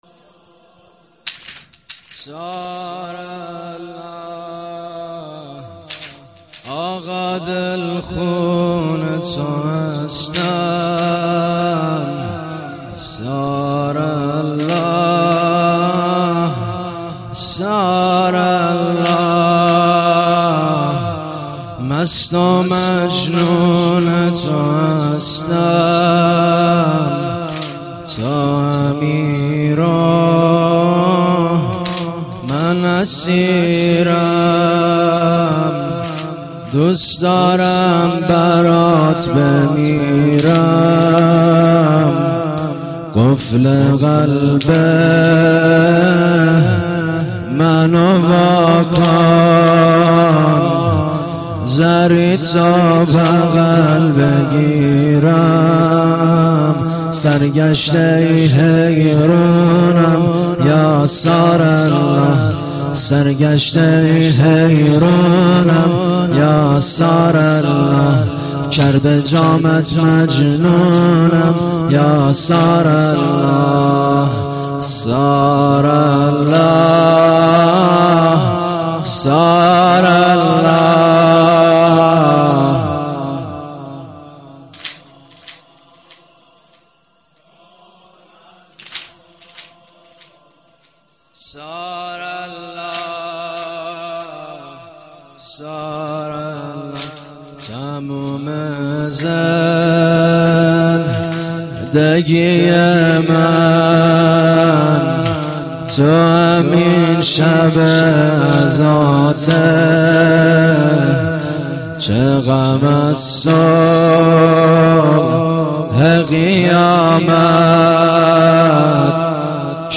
واحد - ثارالله